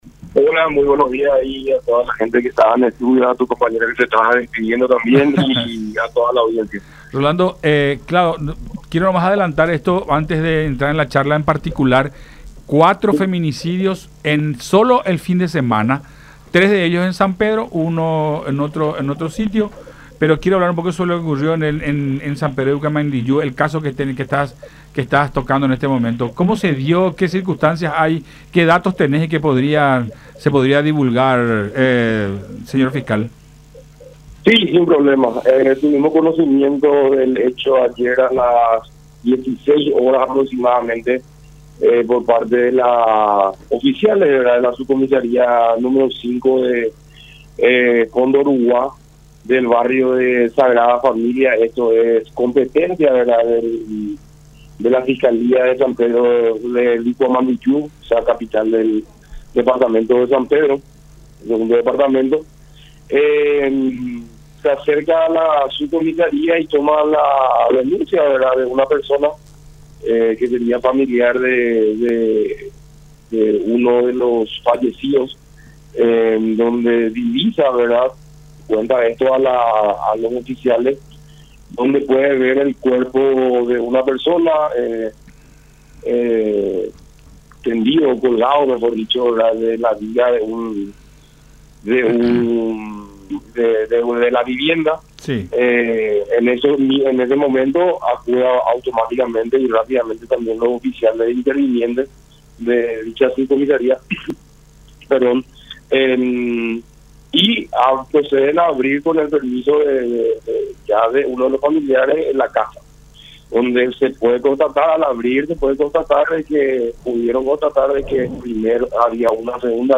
Lamentablemente nos encontramos con una escena demasiado fuerte”, expresó el fiscal del caso, Rolando Ortega, en diálogo con Enfoque 800 por La Unión.